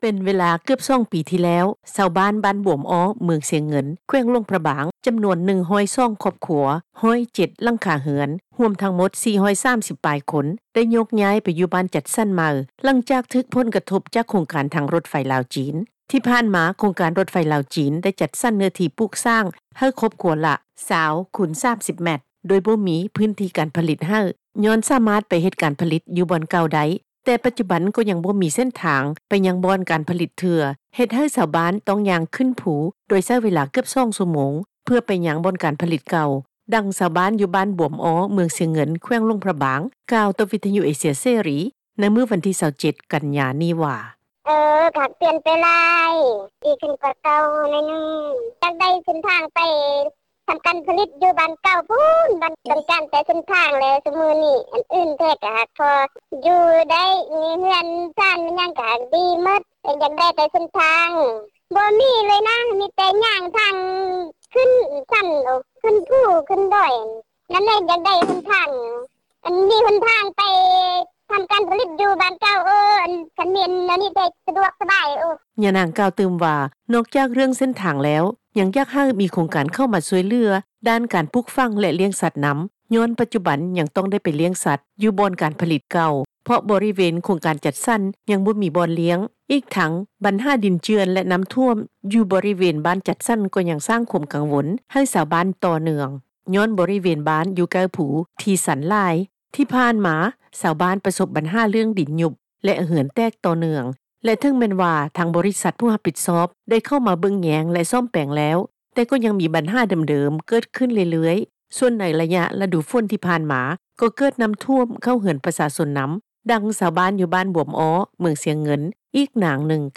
ດັ່ງຊາວບ້ານ ຢູ່ບ້ານບວມອໍໍ້ ເມືອງຊຽງເງິນ ແຂວງຫລວງພຣະບາງ ກ່າວຕໍ່ວິທຍຸເອເຊັຽ ເສຣີ ໃນມື້ວັນທີ່ 27 ກັນຍາ ນີ້ວ່າ:
ດັ່ງຊາວບ້ານບວມອໍ້ ເມືອງຊຽງເງິນ ອີກນາງນຶ່ງ ກ່າວວ່າ:
ດັ່ງ ເຈົ້າໜ້າທີ່ທີ່ກ່ຽວຂ້ອງ ທ່ານນຶ່ງ ກ່າວວ່າ: